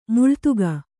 ♪ muḷtuga